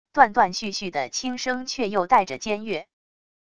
断断续续的轻声却又带着尖悦wav音频